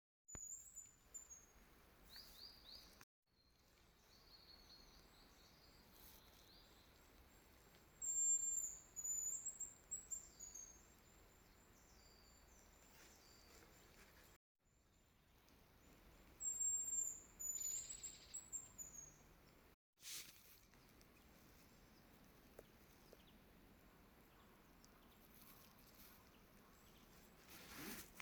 Hazel Grouse, Bonasa bonasia
Ziņotāja saglabāts vietas nosaukumsMežs